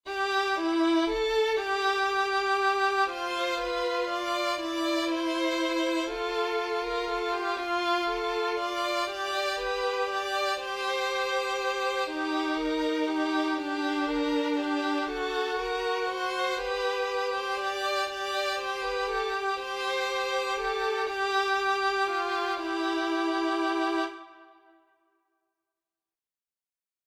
This is a simple vocal or violin obligato.
Voicing/Instrumentation: SA , 2 part choir , Duet , Violin Duet/Violin Ensemble Member(s) , Young Women Voices We also have other 61 arrangements of " Come, Follow Me ".
Includes Vocal Obbligato/Descant